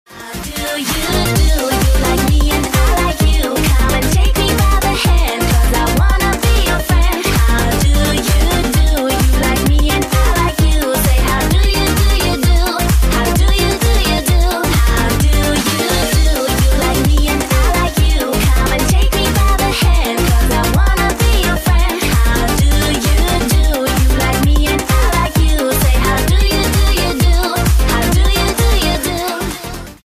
• Качество: 128, Stereo
ритмичные
громкие
женский вокал
веселые
dance
Electronic
EDM
электронная музыка
progressive house